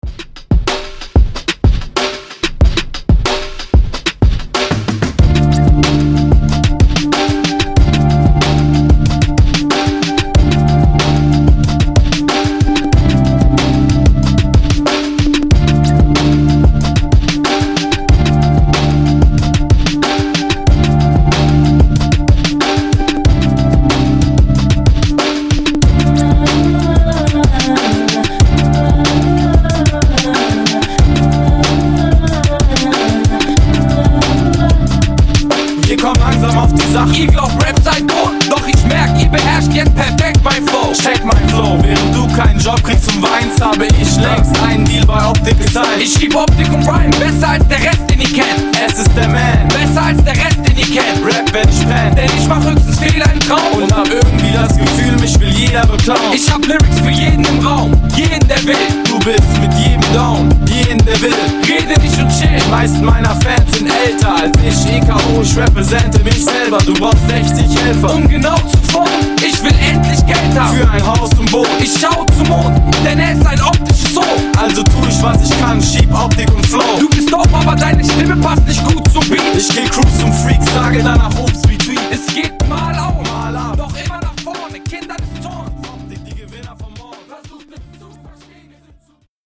Genre: DANCE Version: Clean BPM: 127 Time